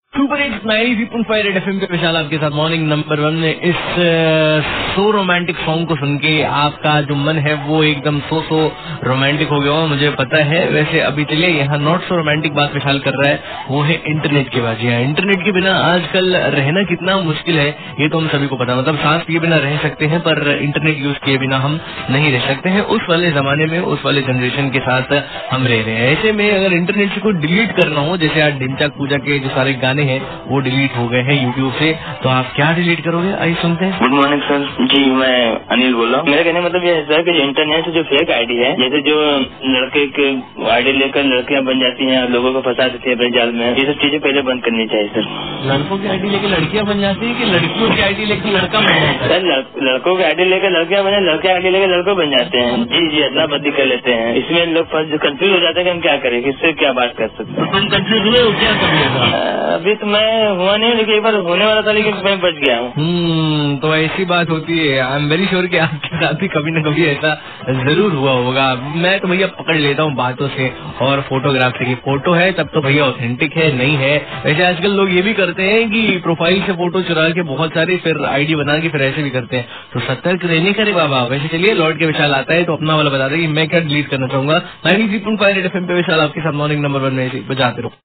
CALLER BYTE ABOUT INTERNET USING